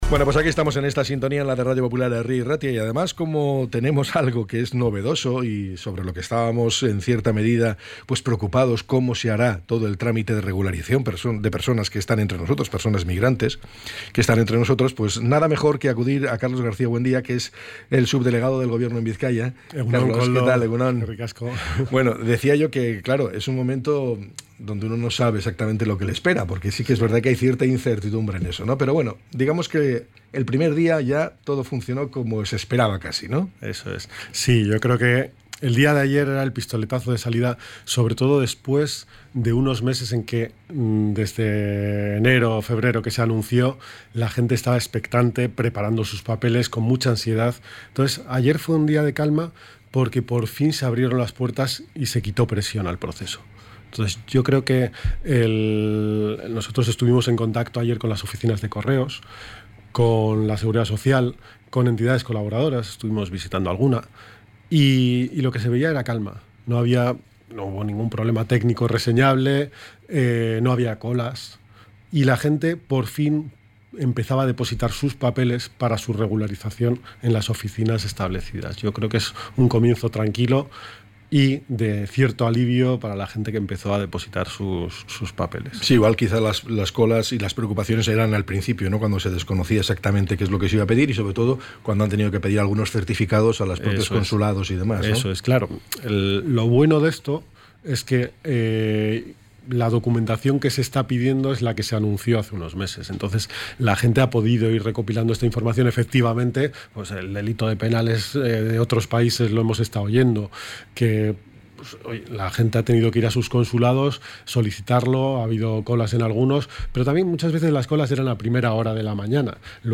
ENTREV.-SUBDELEGADO-GOBIERNO.mp3